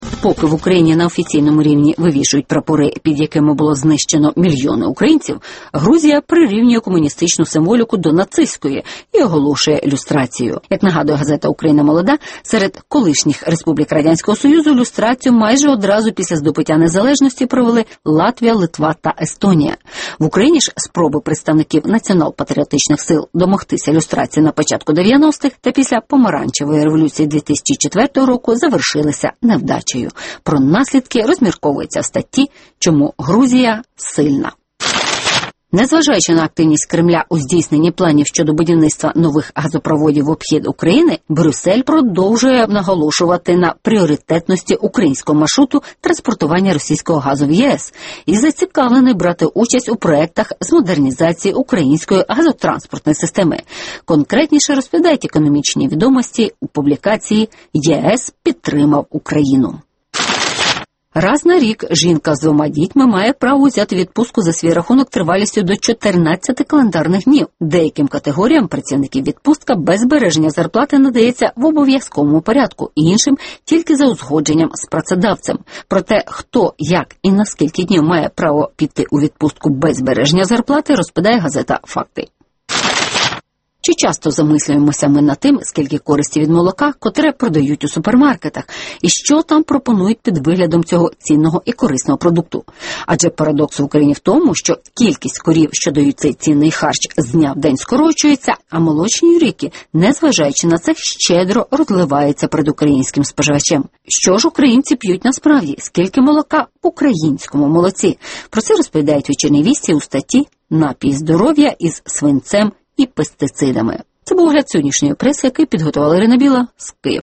Територіальні громади хочуть самостійності (огляд преси)